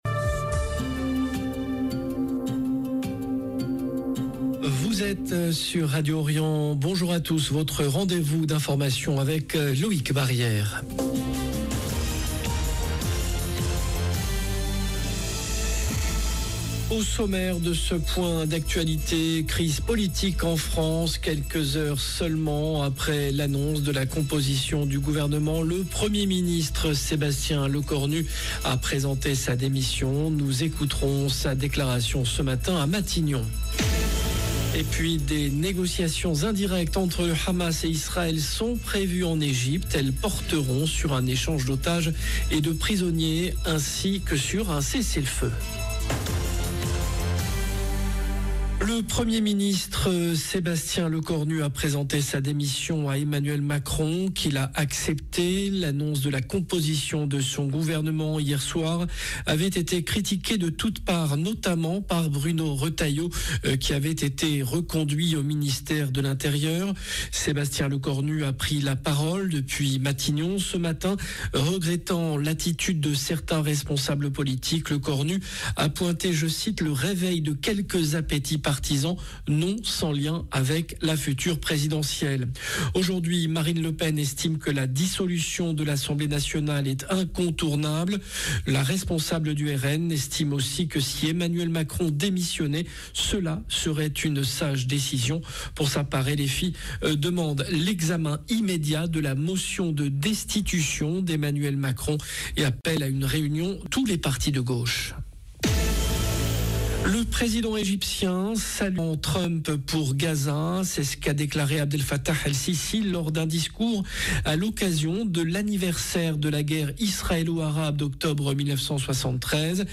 JOURNAL DE MIDI DU 06/10/2025
Quelques heures seulement après l’annonce de la composition du gouvernement, le Premier Ministre Sébastien Lecornu a présenté sa démission. Nous écouterons sa déclaration ce matin à Matignon.